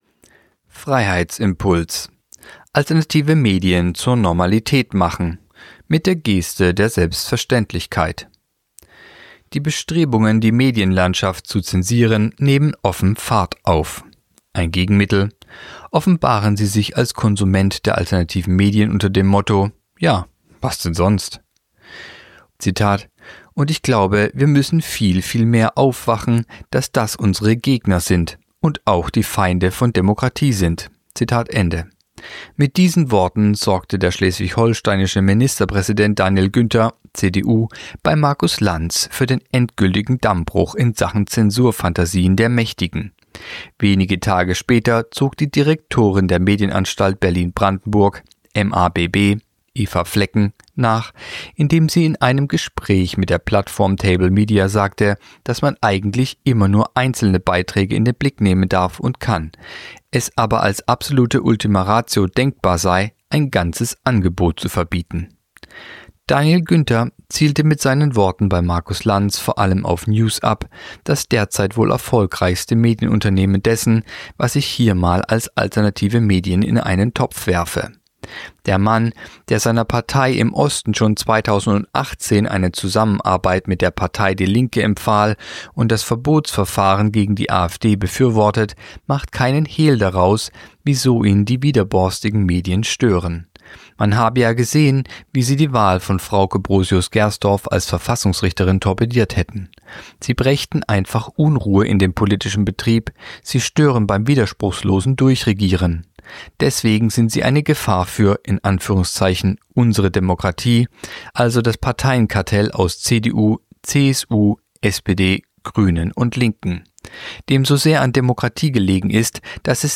Kolumne der Woche (Radio): Freiheitsimpuls